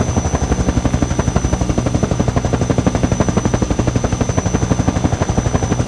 chopperFlyingLoop.ogg